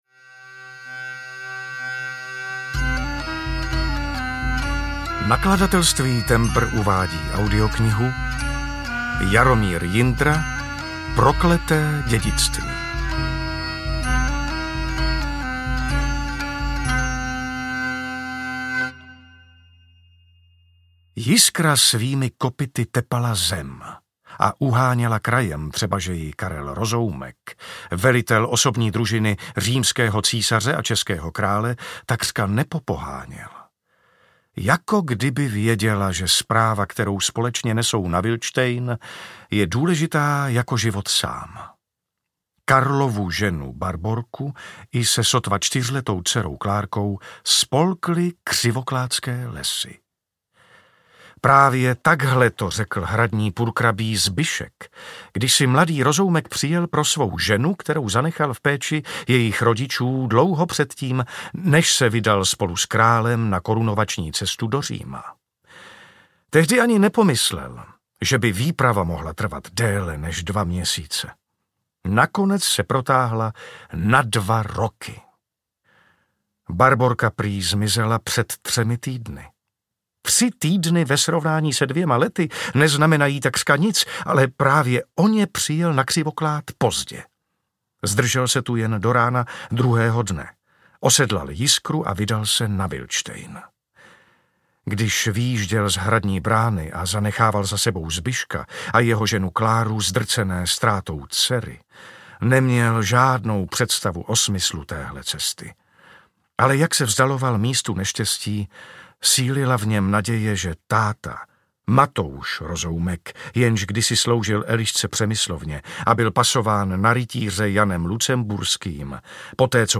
Karel IV. – Prokleté dědictví audiokniha
Ukázka z knihy